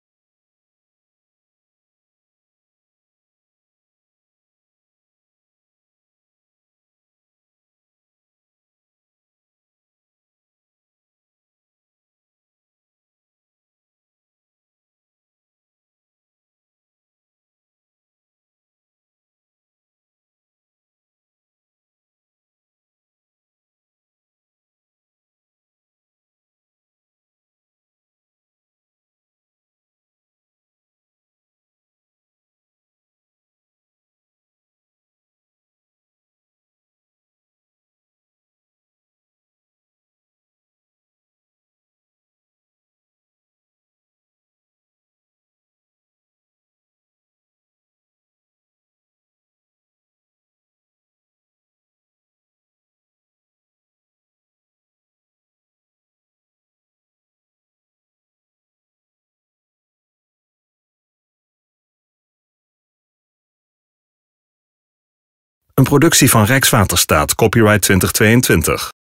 VROLIJKE MUZIEK TOT HET EIND VAN DE VIDEO